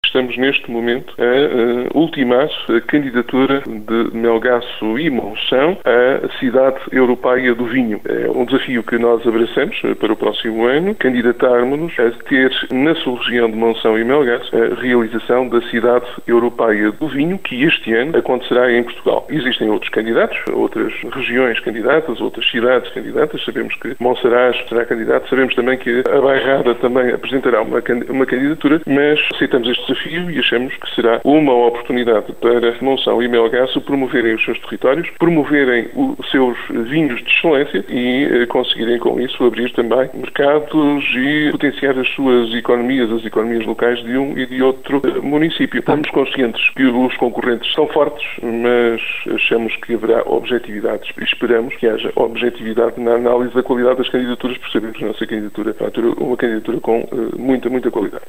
Monção e Melgaço, municípios da sub-região do Alvarinho vão apresentar candidatura, mas pela frente vão ter concorrentes de peso como a Bairrada e Reguengos de Monsaraz. O autarca de Melgaço, Manuel Baptista, diz estar confiante na vitória: